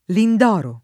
vai all'elenco alfabetico delle voci ingrandisci il carattere 100% rimpicciolisci il carattere stampa invia tramite posta elettronica codividi su Facebook Lindoro [ lind 0 ro ] pers. m. — personaggio della commedia dell’arte (e poi di C. Goldoni e di G. Rossini)